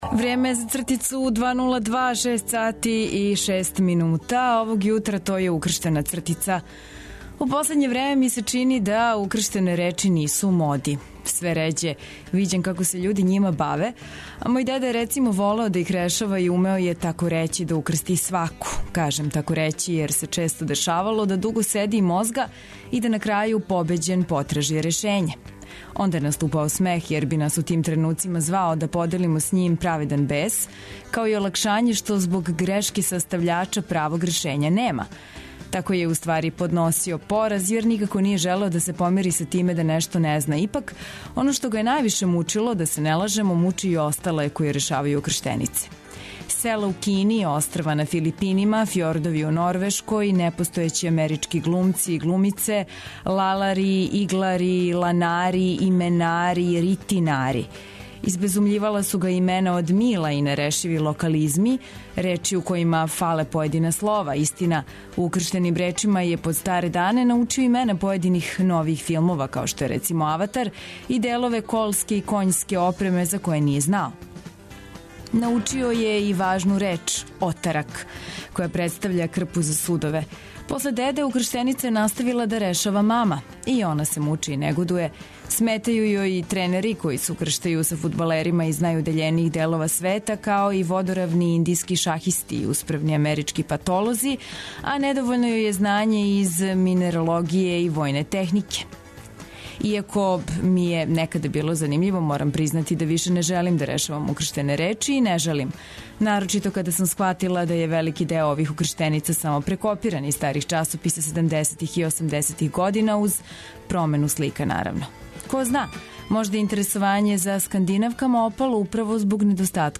Чућете и "Кратке резове", "Кваку", "Минивал", лутајућег репортера са београдских улица и још много тога. Уз добру музику, наравно.